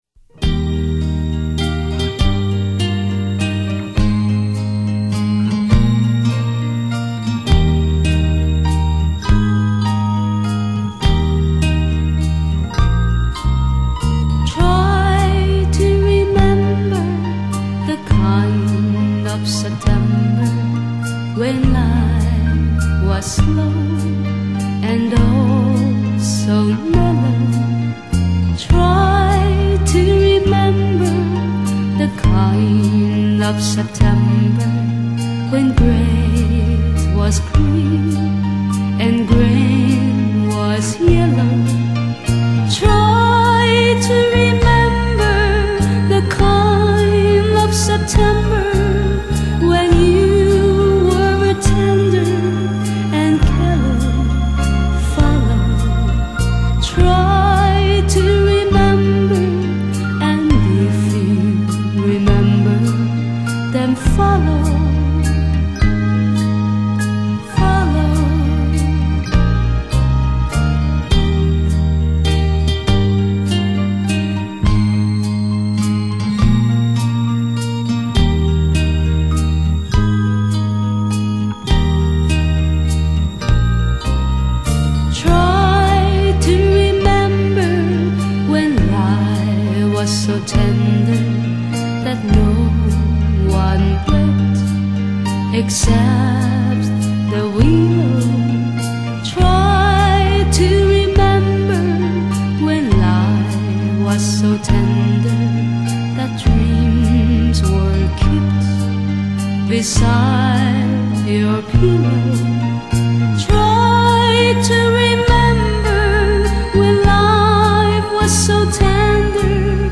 语    种：纯音乐
低吟浅唱，有如天籁，直触人心，荡埃涤尘。木吉他的朴素和弦，民谣歌手的真情演绎，帮我们寻回人类的童真，生活的安宁。